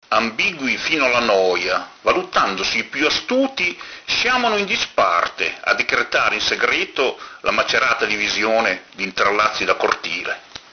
legge le sue poesie